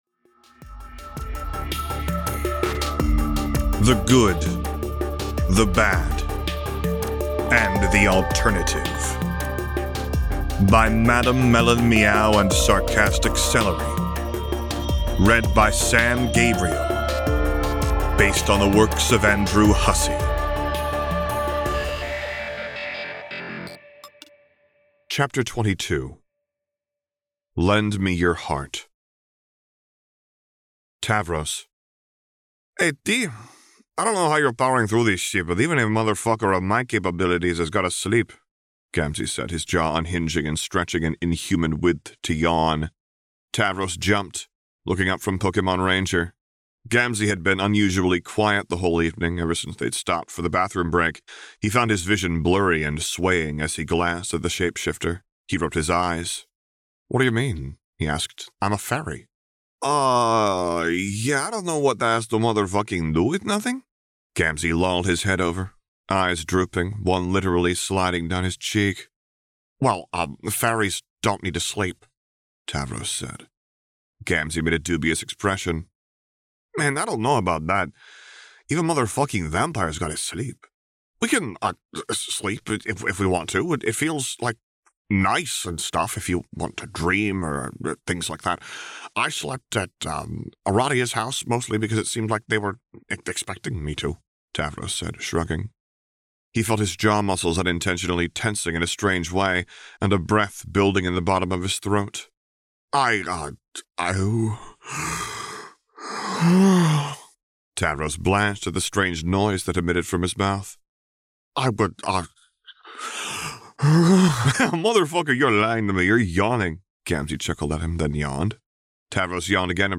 This story consists of one main narrative and multiple ancillary texts, recorded and spliced in order on this feed.